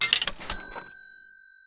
cha-ching.mp3